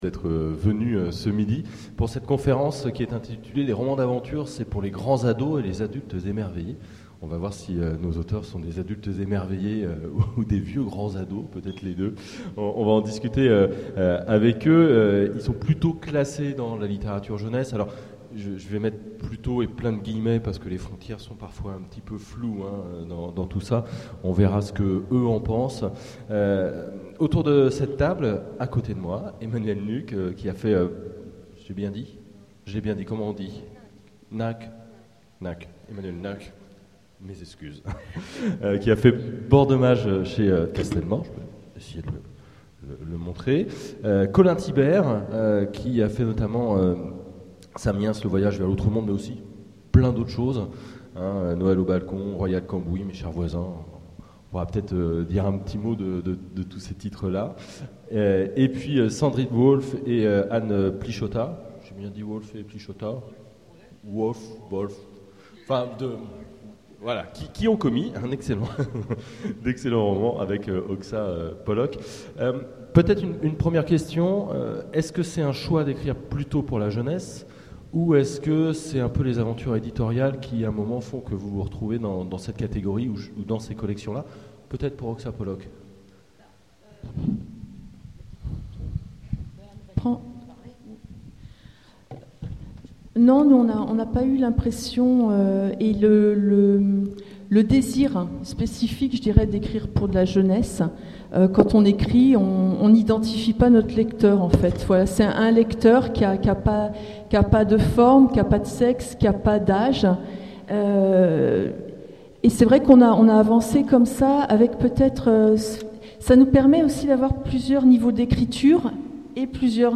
Mots-clés Aventure Conférence Partager cet article